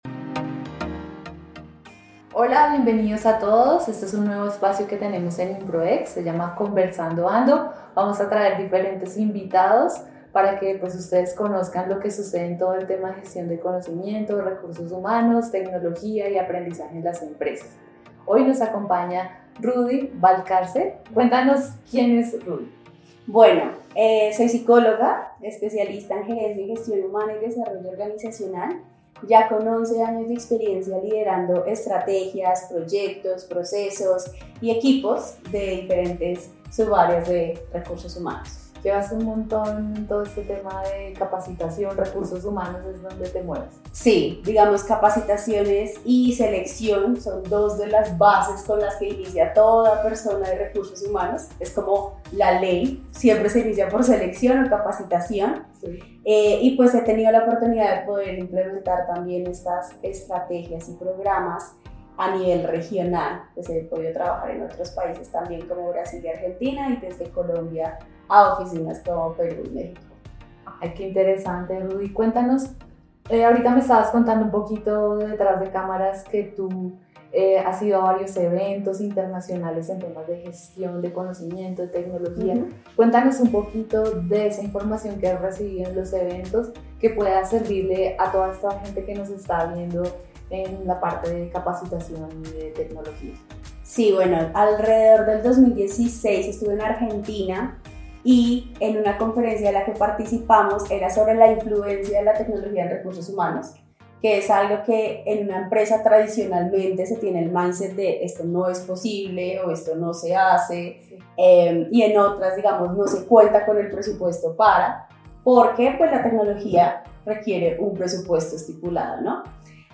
Aquí podrás encontrar entrevistas de destacados directores de recursos humanos de todo el mundo.